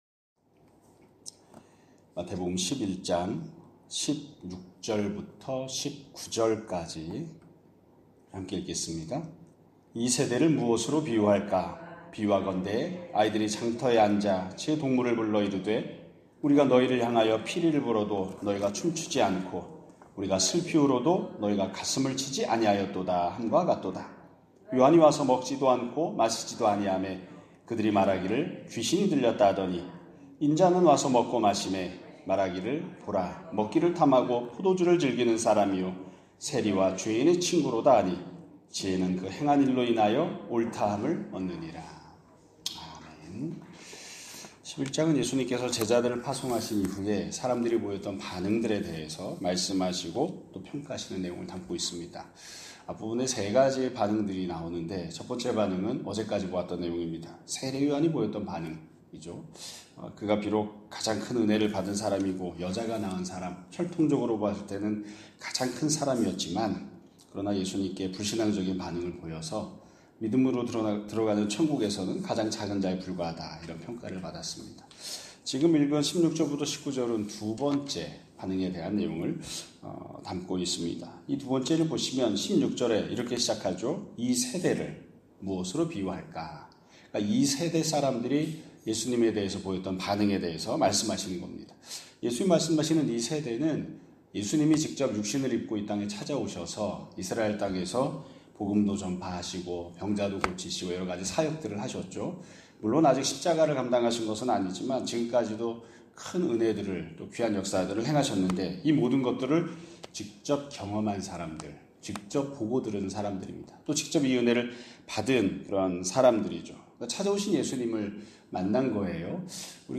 2025년 8월 27일 (수요일) <아침예배> 설교입니다.